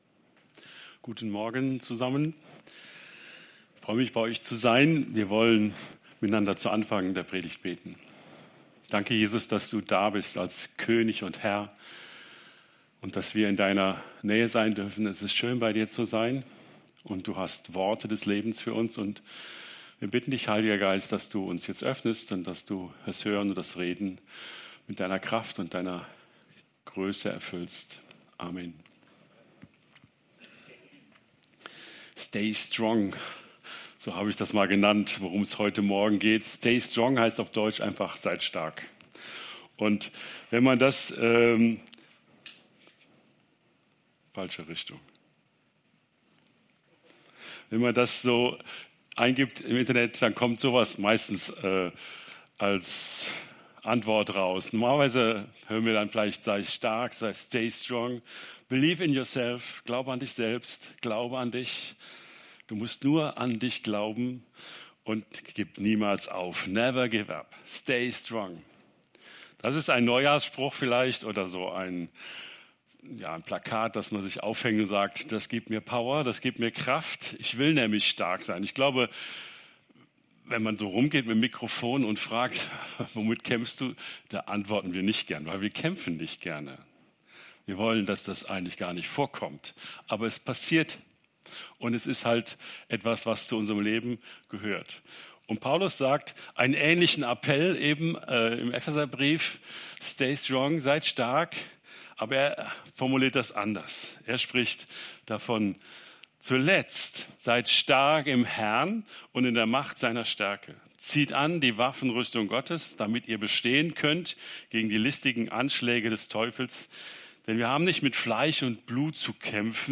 Predigt vom 18.01.2026 | Podcast der Stadtmission Alzey